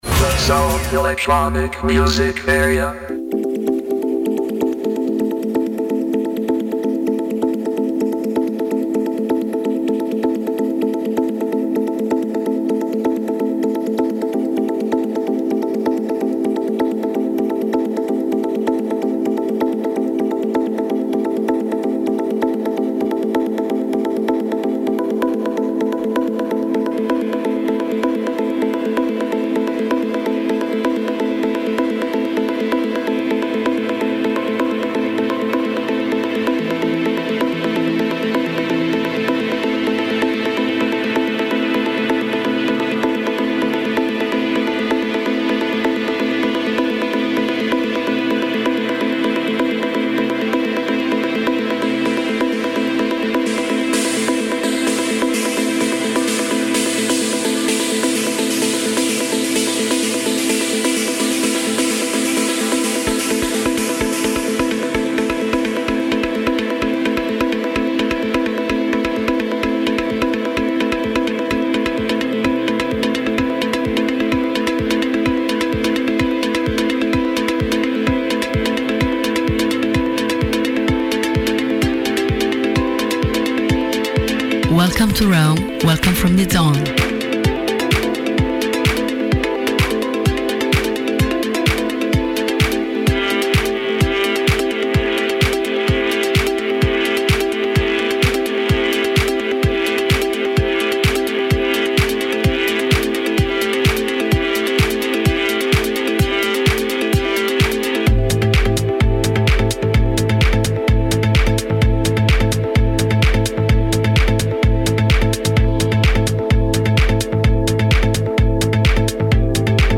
ON-AIR LIVE
For all the Electronic/Electro music lovers!